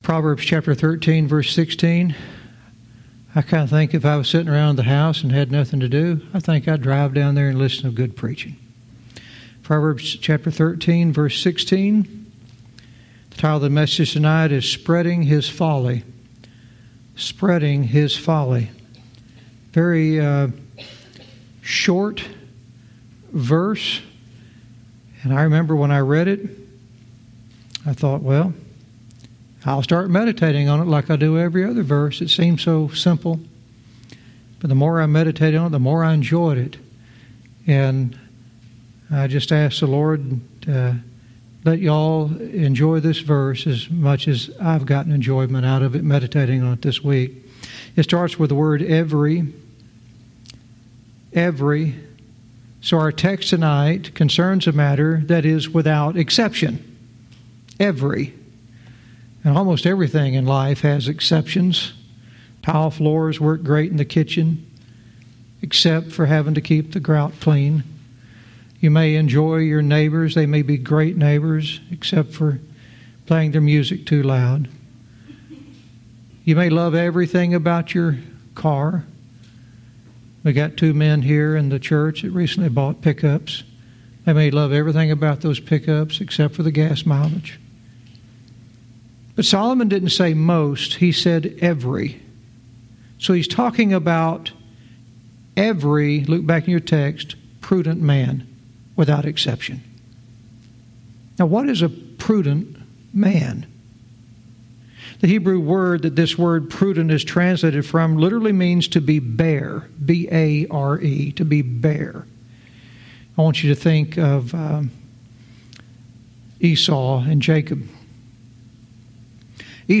Verse by verse teaching - Proverbs 13:16 "Spreading His Folly"